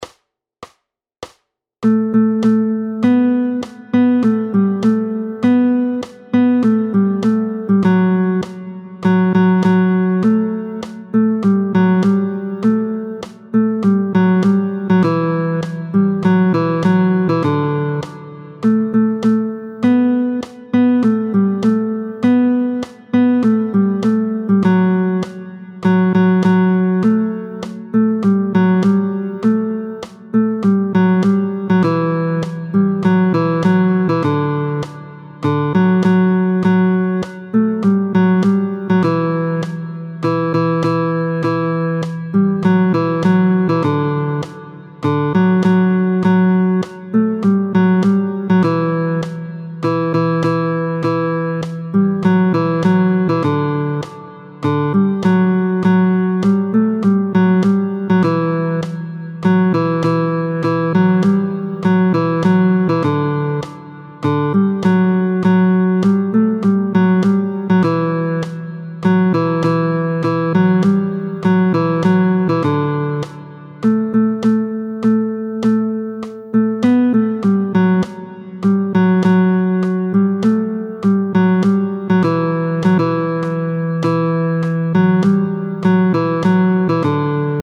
نسخه: آسان
√ برای ساز گیتار | سطح آسان